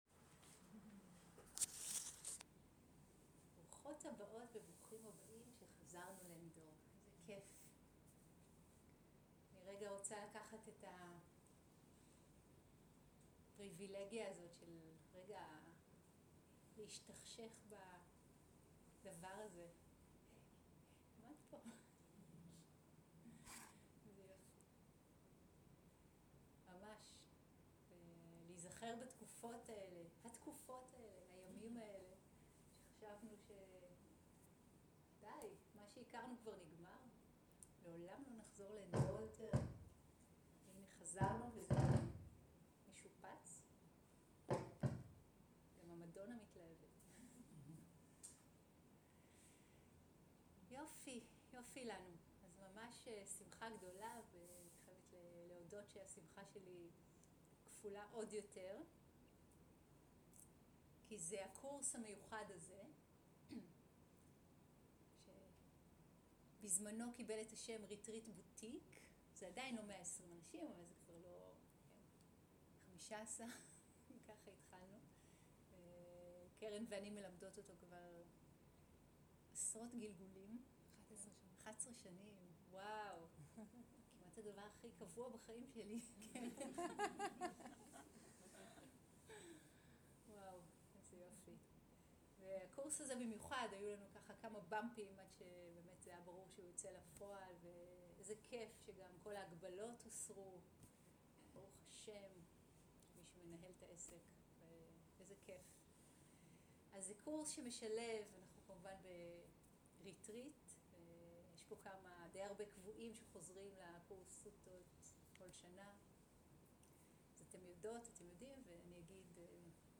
Dharma type: Opening talk שפת ההקלטה